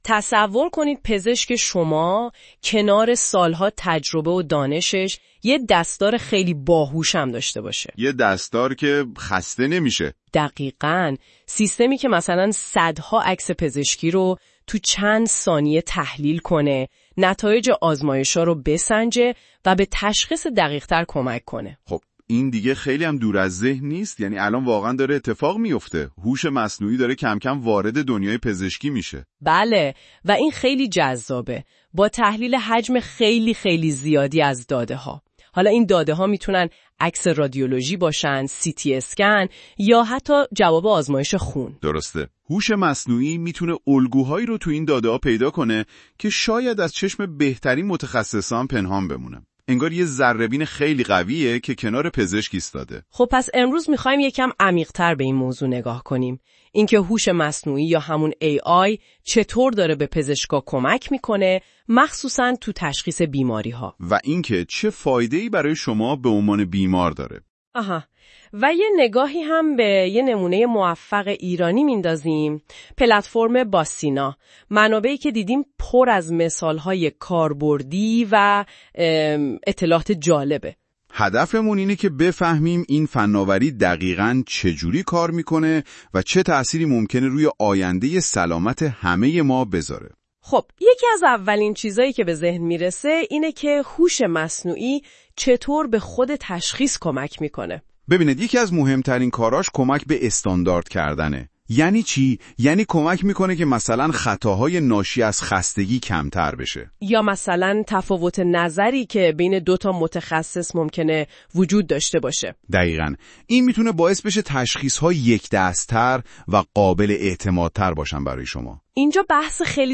🎧 بشنوید: هوش مصنوعی چگونه به تشخیص دقیق‌تر بیماری‌ها کمک می‌کند؟ در این گفتگوی کوتاه، با نقش هوش مصنوعی در بهبود فرآیندهای تشخیصی، مزایای آن برای بیماران و پزشکان، و تجربه موفق سامانه «باسینا» در ایران آشنا شوید.